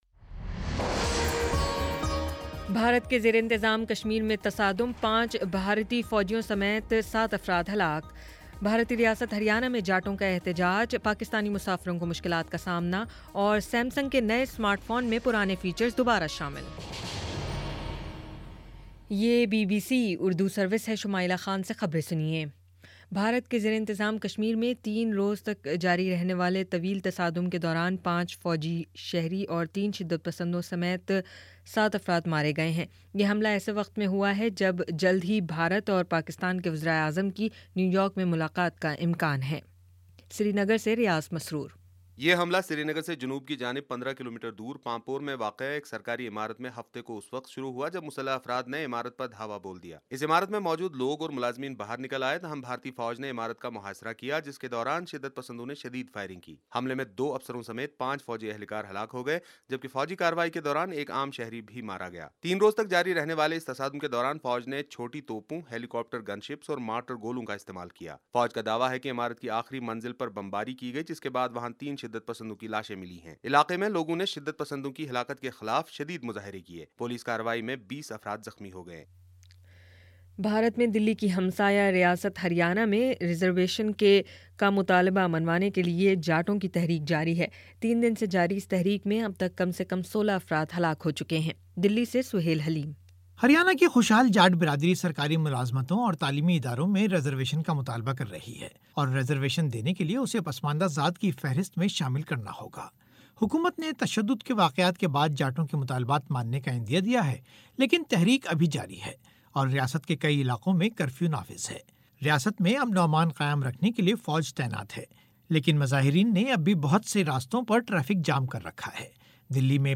فروری 22 : شام پانچ بجے کا نیوز بُلیٹن